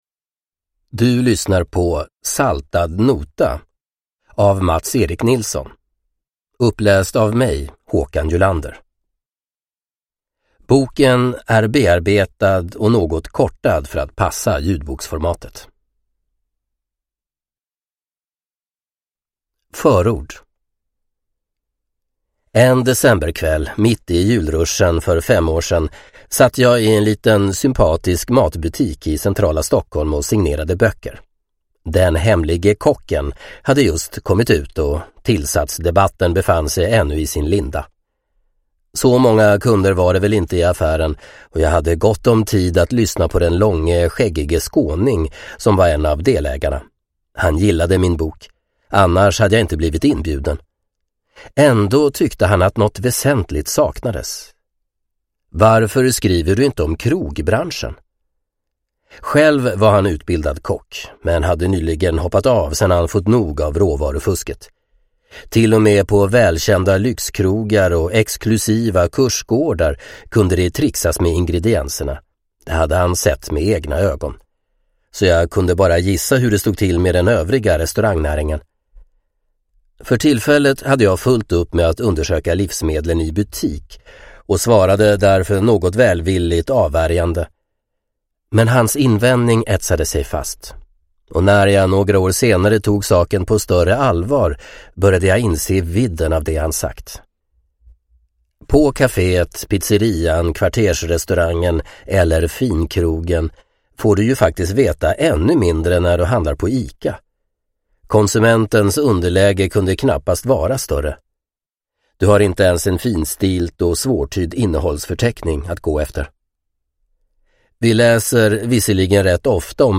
Saltad nota: Om matfusket - från gatukök till gourmetkrog – Ljudbok – Laddas ner